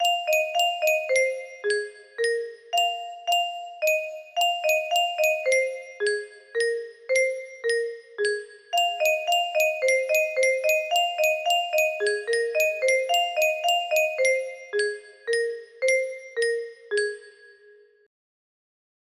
¿ music box melody